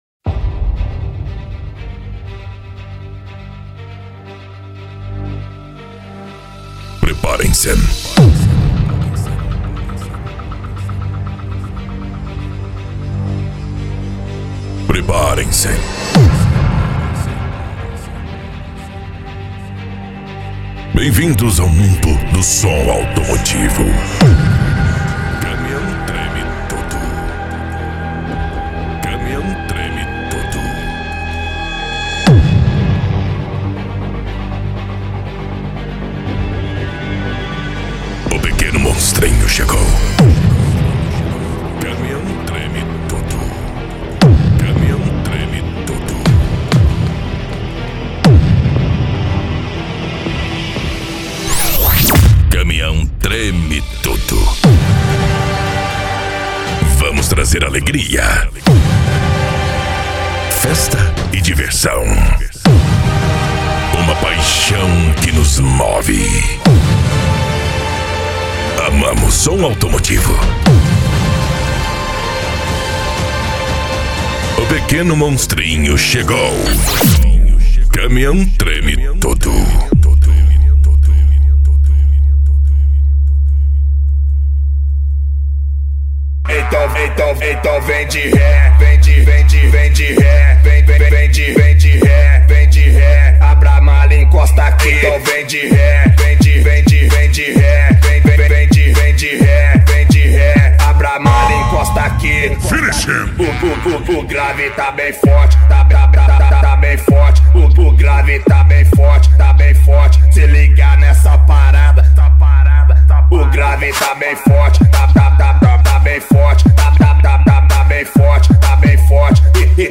Modao
PANCADÃO
Remix